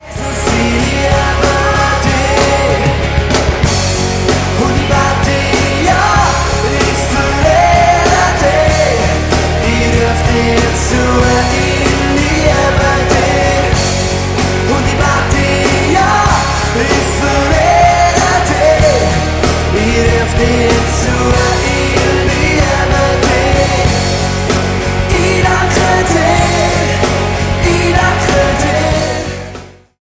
Diese Worship-CD wurde am Crea Meeting live aufgenommen.